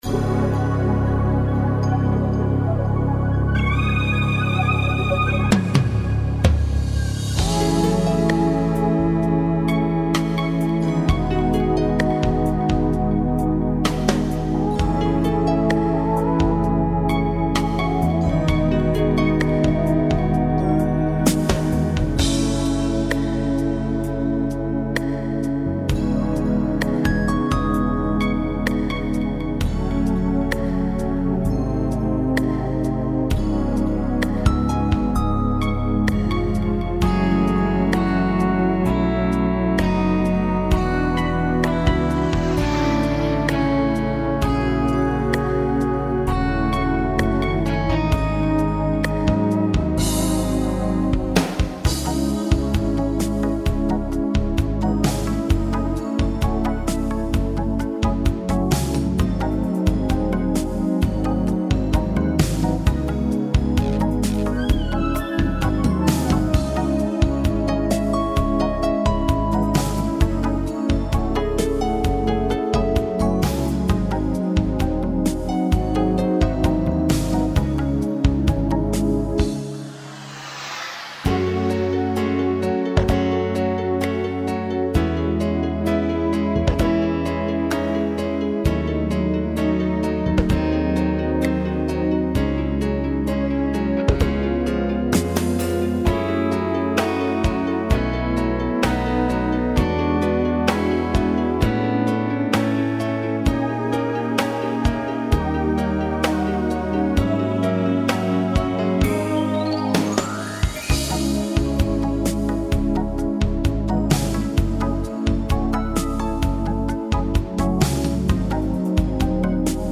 В первой-минус, только он и есть в Сети.